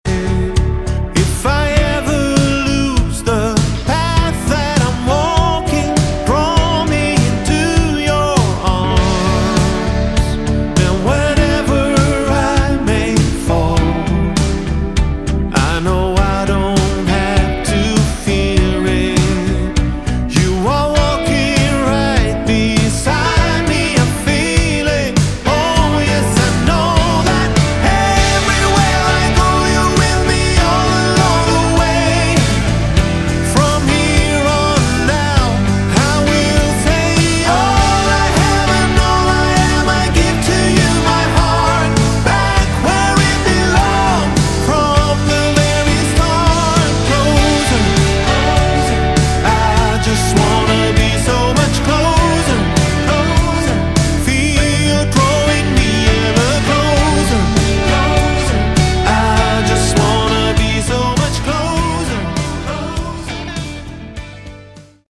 Category: AOR / Melodic Rock
lead vocals, guitars
keyboards
bass
drums